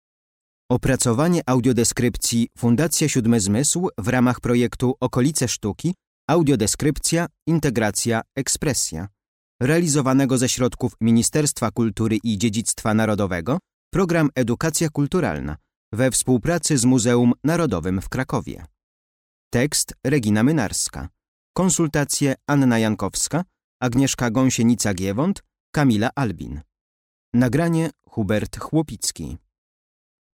Audiodeskrypcja - Muzeum Szymanowskiego